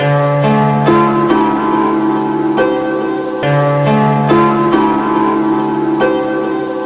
CLIFFHANGER_140_D#.wav